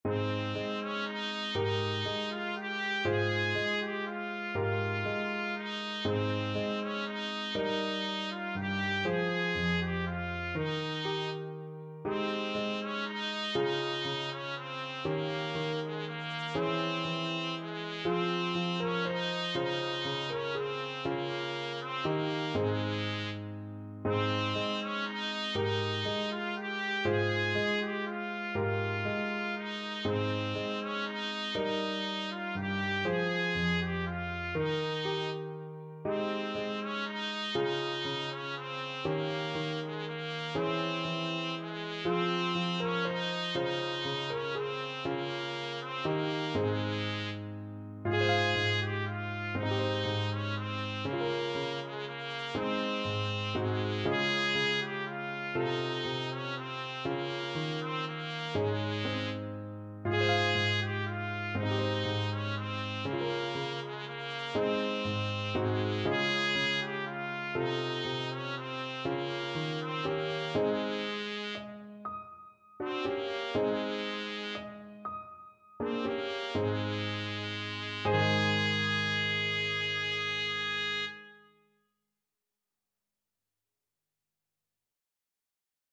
Traditional Trad. El Noi de la Mare Trumpet version
Trumpet
Lentissimo .= 40
6/8 (View more 6/8 Music)
Ab4-Ab5
Ab major (Sounding Pitch) Bb major (Trumpet in Bb) (View more Ab major Music for Trumpet )
Traditional (View more Traditional Trumpet Music)